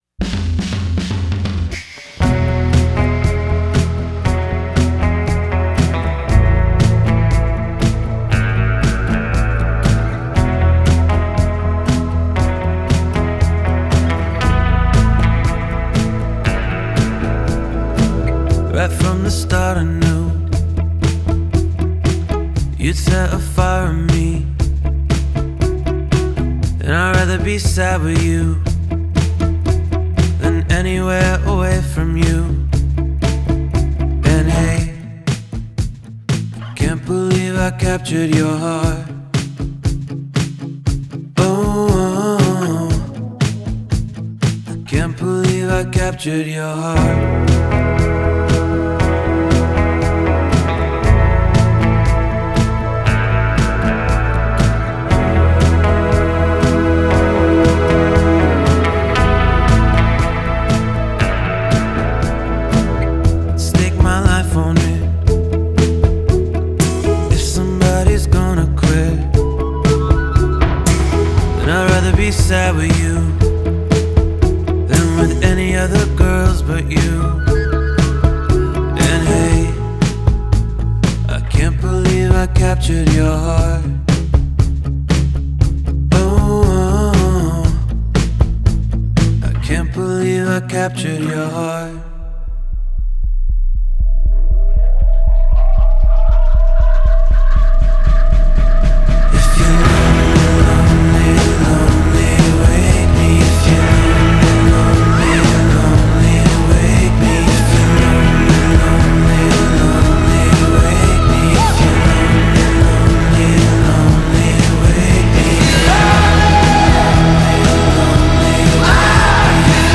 loping rhythm